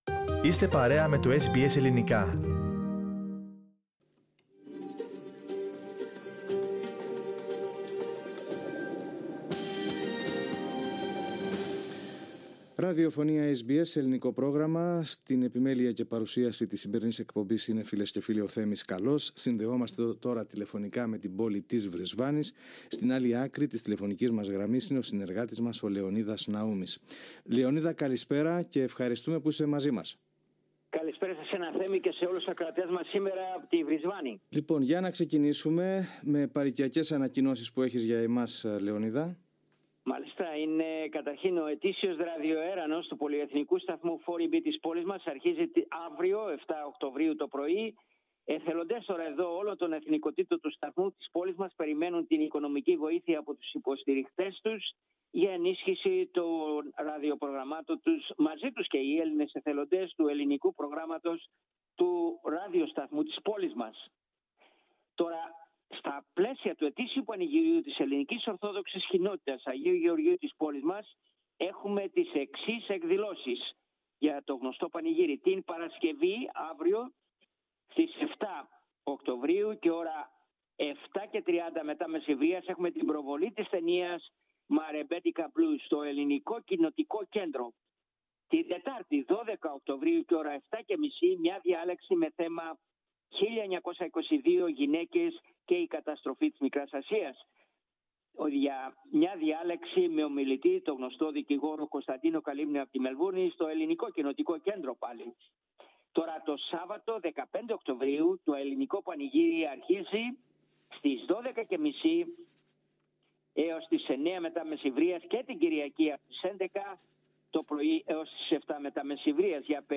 Ακούστε την εβδομαδιαία ανταπόκριση από την Βρισβάνη της Κουηνσλάνδης.